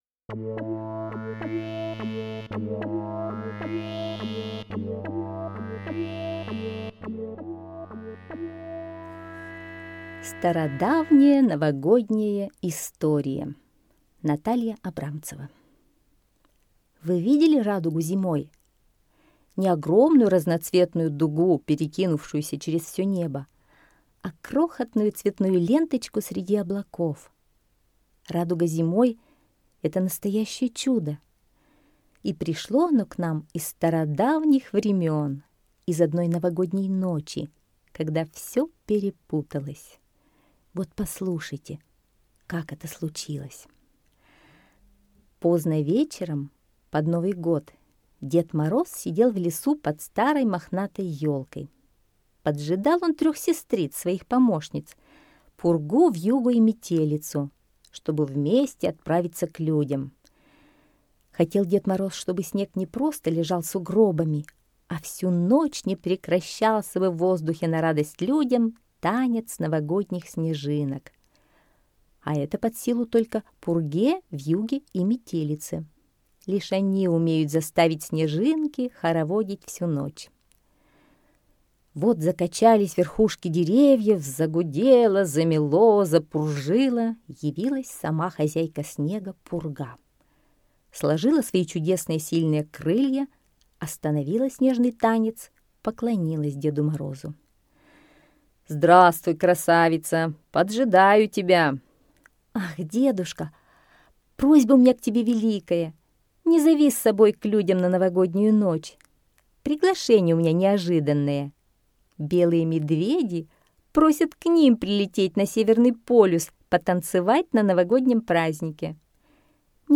Слушайте Стародавняя новогодняя история - аудиосказка Абрамцевой Н. Однажды перед Новым Годом Дед Мороз ждал своих помощниц.